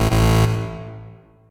Techmino/media/effect/chiptune/finesseError_long.ogg at 89134d4f076855d852182c1bc1f6da5e53f075a4
finesseError_long.ogg